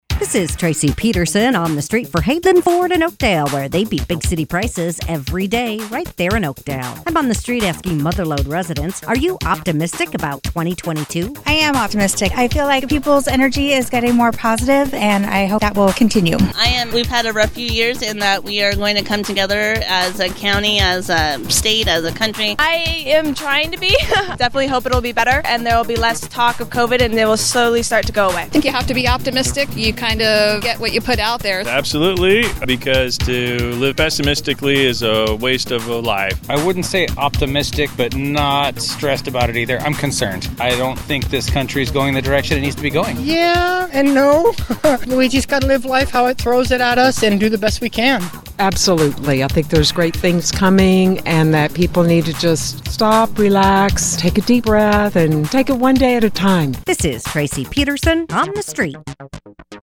asks Mother Lode residents, “Are you optimistic about 2022?”